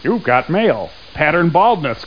male_pattern.mp3